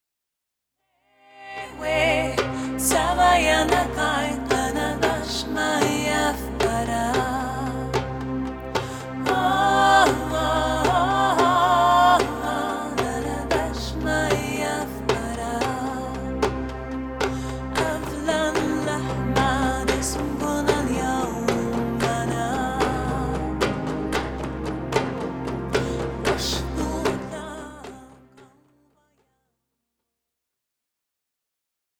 Dans un style pop saupoudré de musique du monde